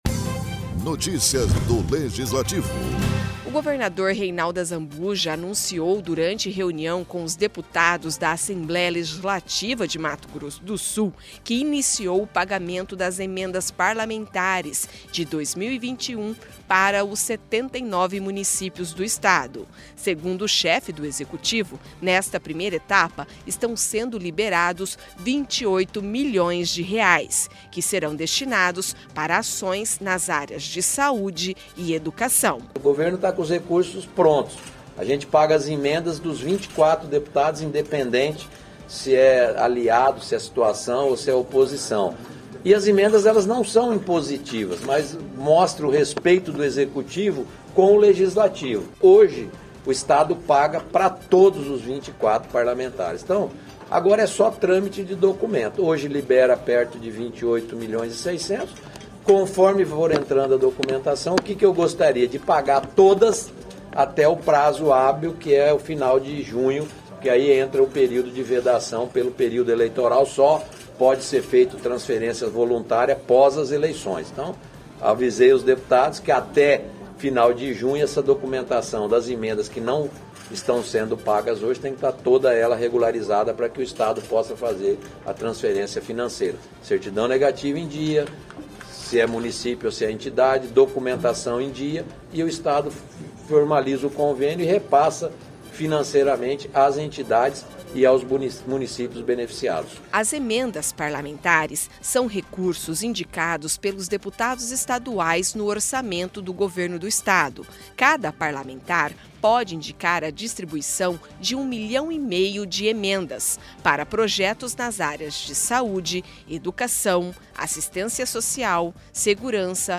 Em reunião na ALEMS, governador anuncia pagamento de R$28 milhões em emendas parlamentares